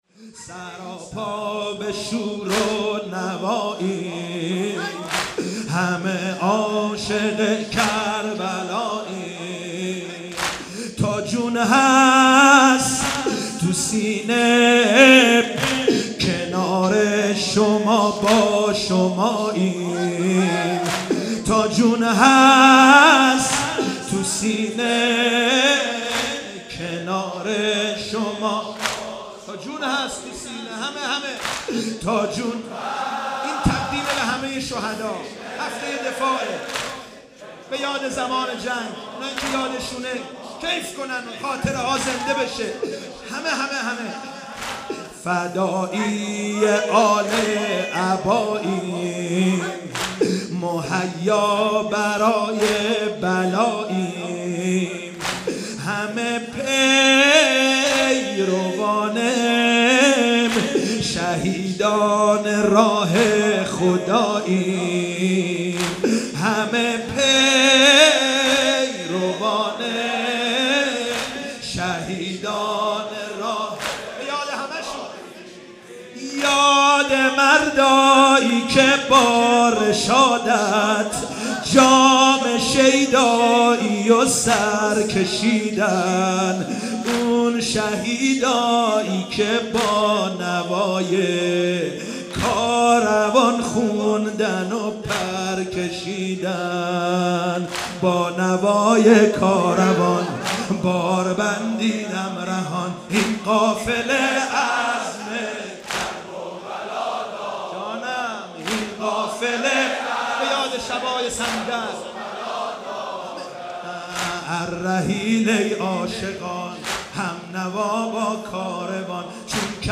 مداحی سید مهدی میرداماد در شب اول محرم الحرام 1396.